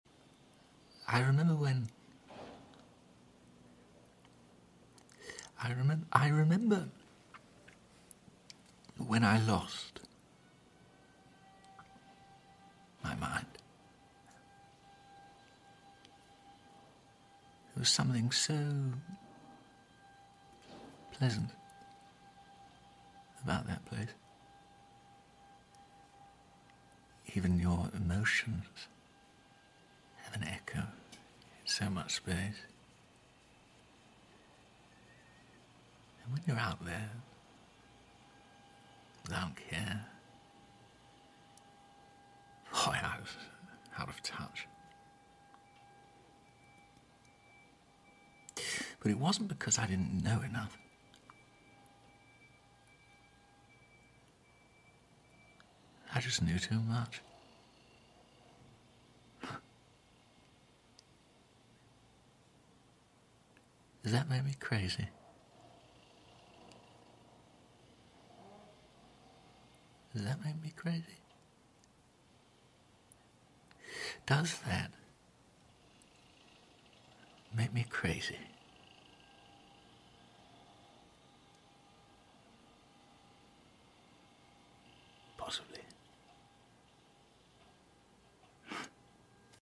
🎭 Dramatic Monologue: Crazy By Sound Effects Free Download